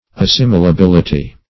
Meaning of assimilability. assimilability synonyms, pronunciation, spelling and more from Free Dictionary.
assimilability.mp3